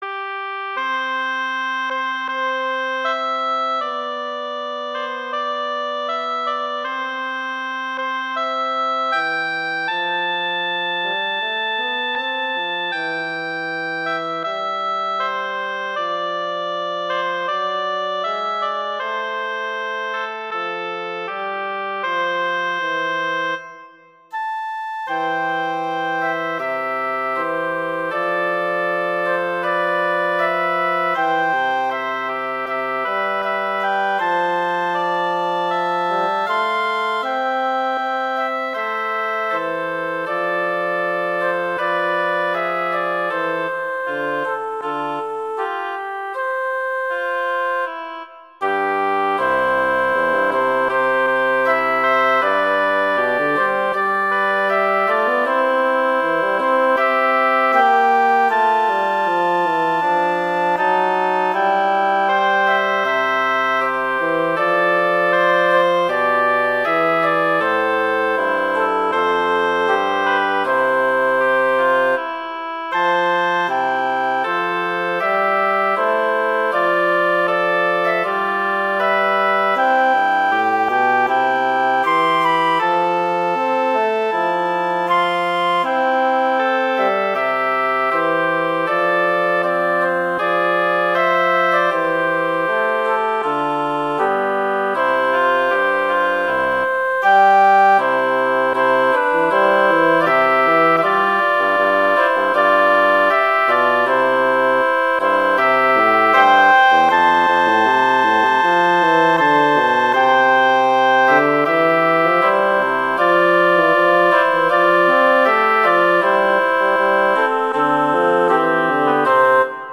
classical, holiday, winter
C major
♩=79 BPM (real metronome 80 BPM)
flute:
clarinet:
bassoon: